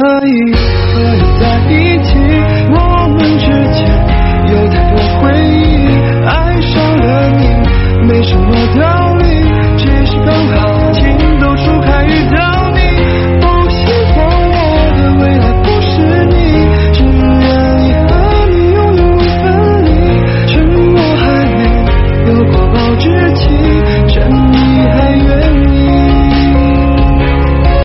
Nhạc Chuông Nhạc Hoa